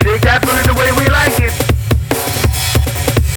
drums06.wav